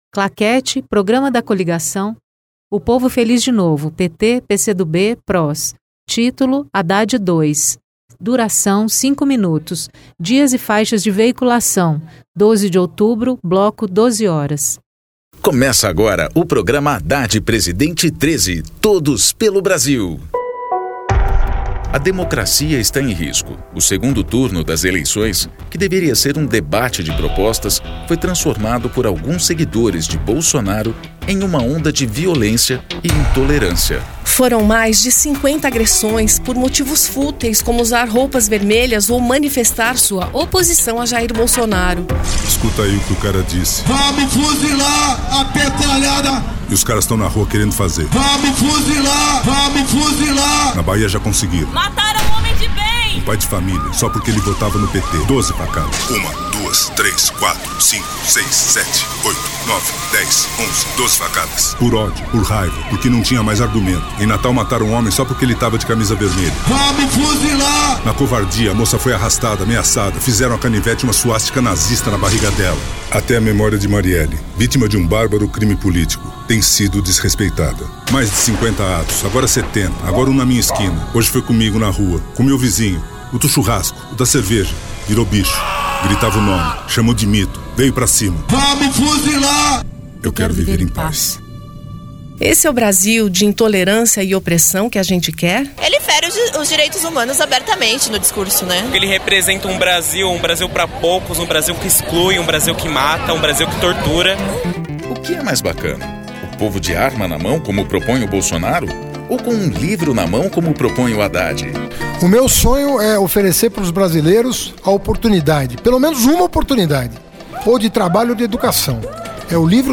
TítuloPrograma de rádio da campanha de 2018 (edição 32)
Gênero documentaldocumento sonoro
Descrição Programa de rádio da campanha de 2018 (edição 32) 2º Turno. 12/10/2018, bloco 12:00hs